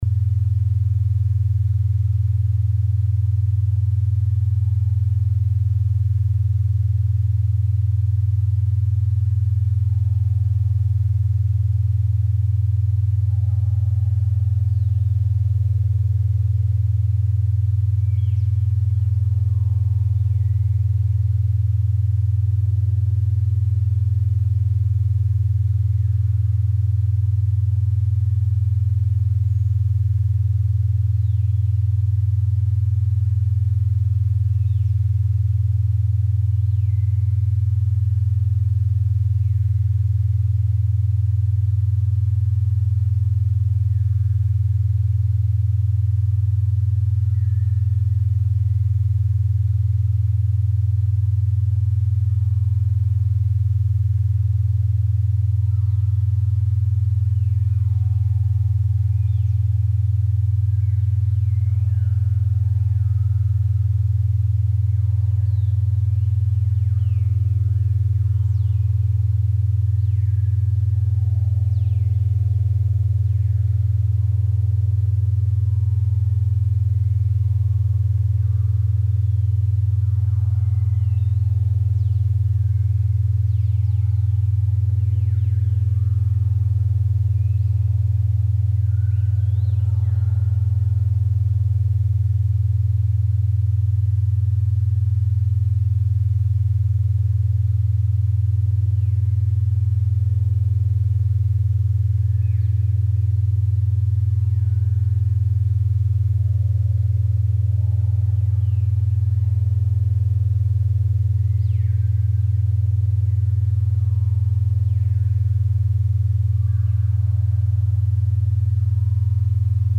Dyslexc DSL - sample - the full version is 30 minutes and consists of left and right channel frequencies maintaining a 20.21Hz difference for 30 minutes. It also has pink noise and synthesized bird sounds.